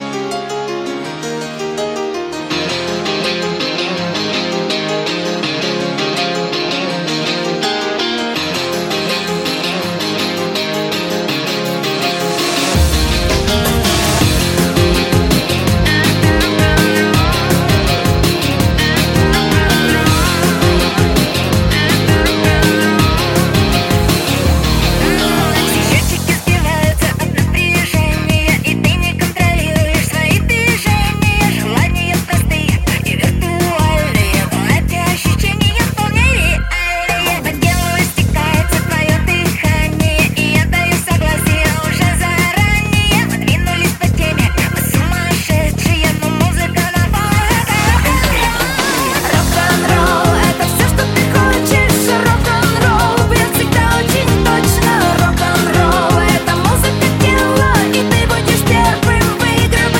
Pop
качественной танцевальной поп-музыки европейского уровня